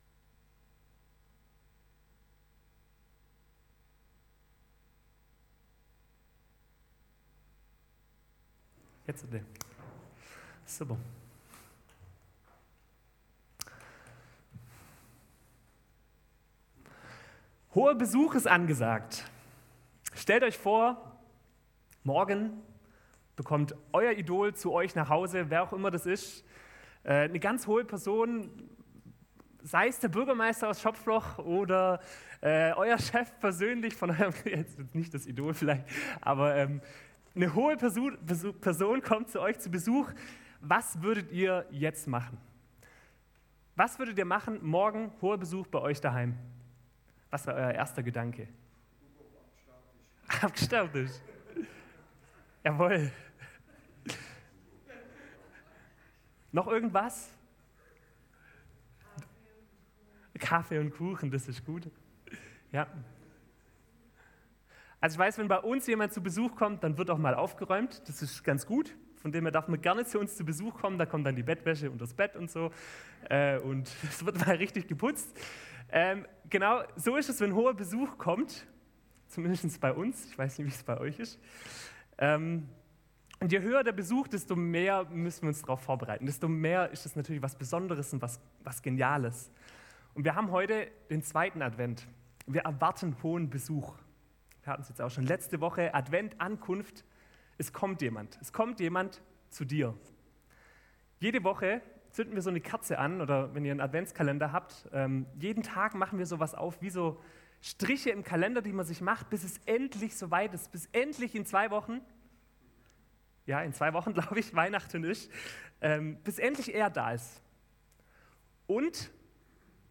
Predigt am 10.12.2023